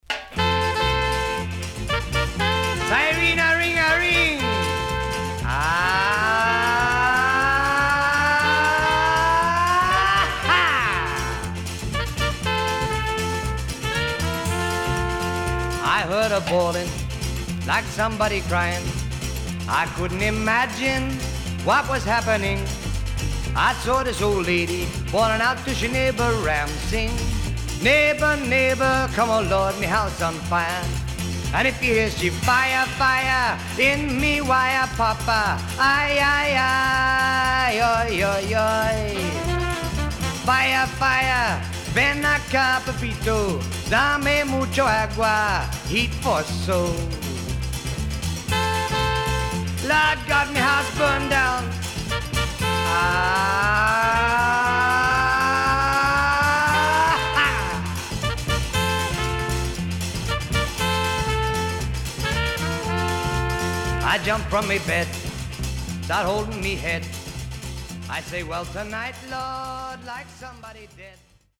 SIDE A:少しノイズあり、曲によってヒスが入りますが良好です。